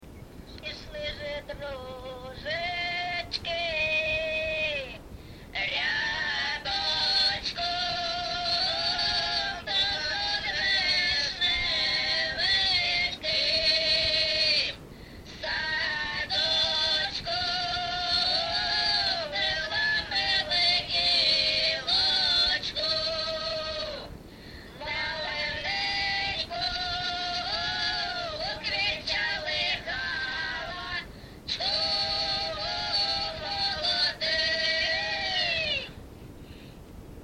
ЖанрВесільні
Місце записус. Некременне, Олександрівський (Краматорський) район, Донецька обл., Україна, Слобожанщина